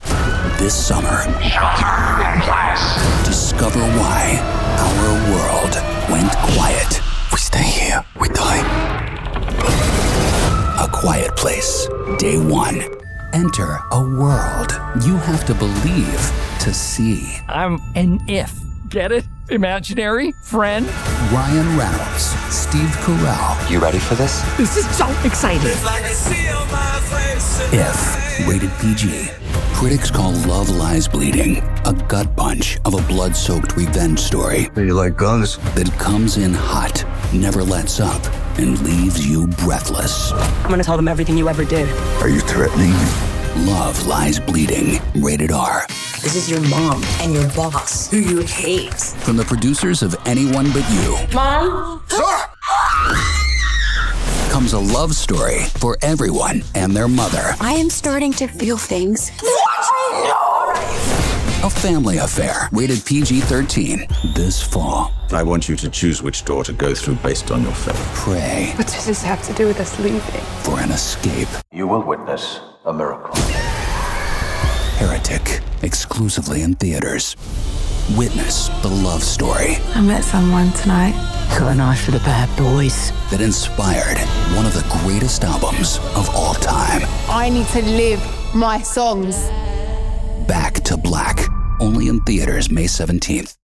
He has a great sincerity to his voice. He can also add a little edge and push into those GenX and movie trailer reads.
announcer, attitude, Booming, compelling, movie-trailer